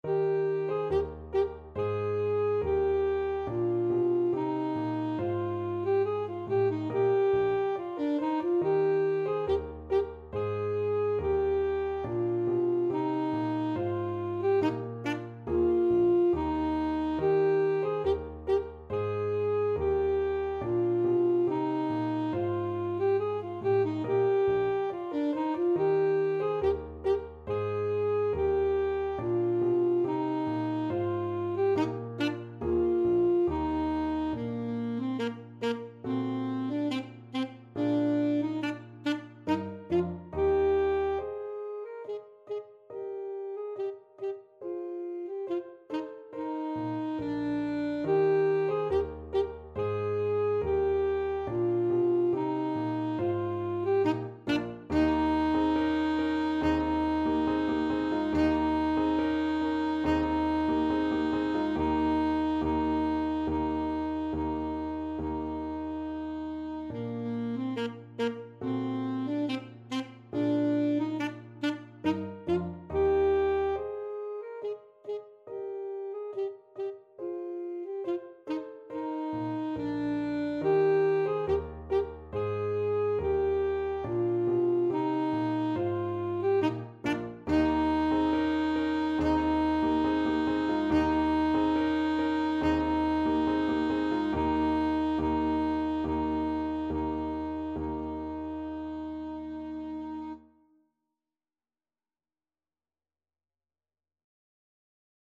Alto Saxophone
2/4 (View more 2/4 Music)
Classical (View more Classical Saxophone Music)